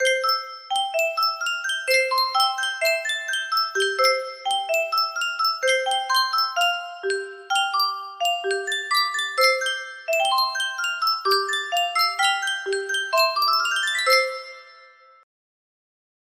Sankyo Music Box - March from the River Kwai LN music box melody
Full range 60